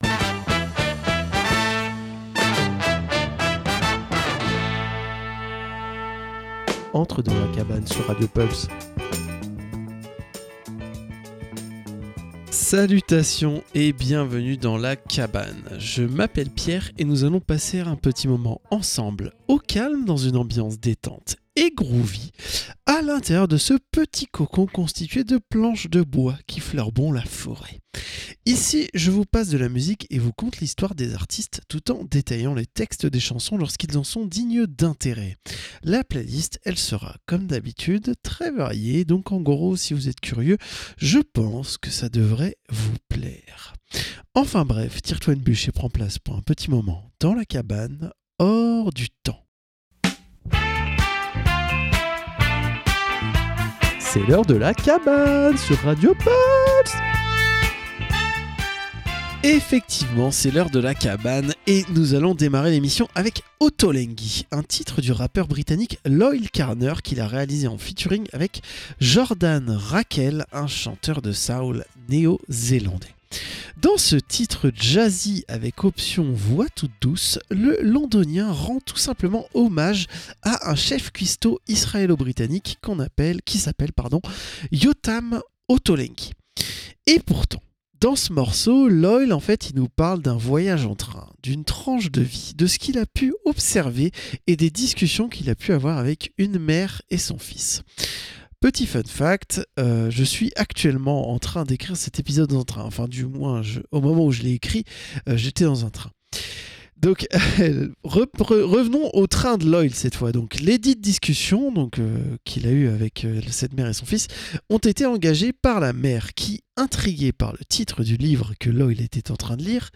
La cabane, détente dans une ambiance chill/groovy avec une playlist éclectique allant du rock à la funk en passant par la pop, le rap ou l'électro. Au programme : écoute et découverte ou redécouverte d'artistes et explication des paroles de grands classiques de la musique !